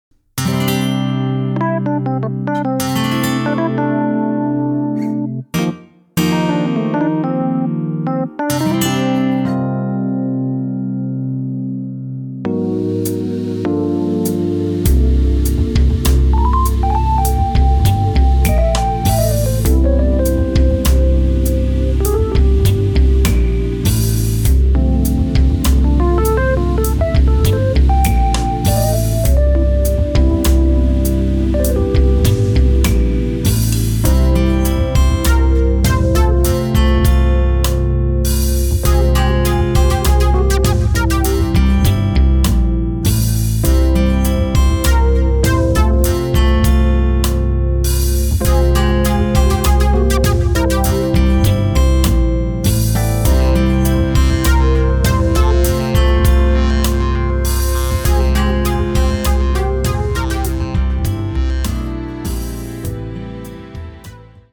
Keyboards
Guitar, Bass